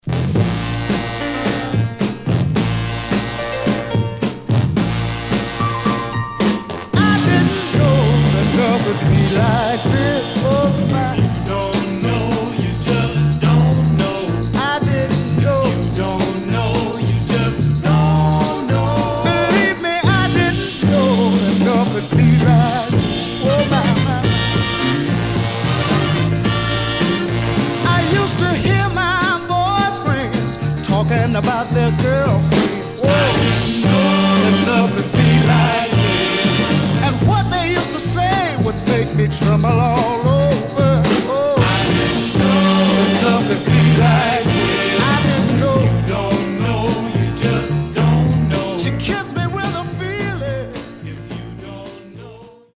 Some surface noise/wear Stereo/mono Mono
Soul